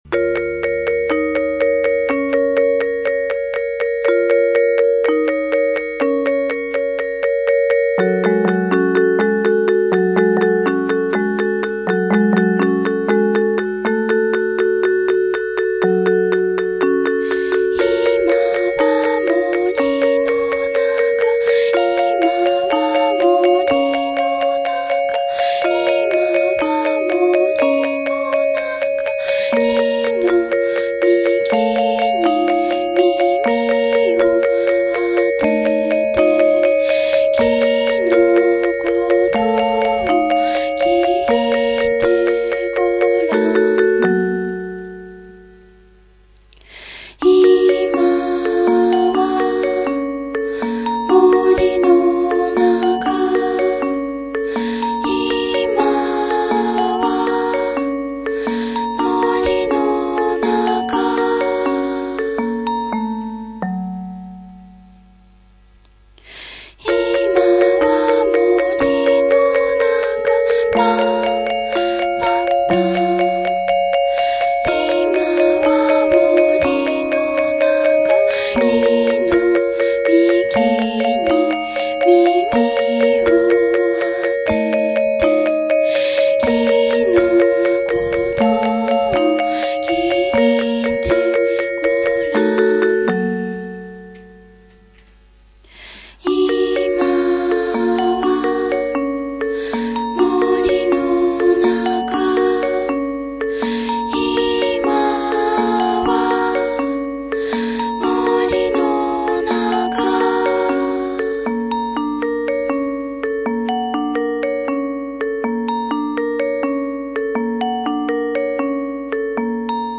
violiniste.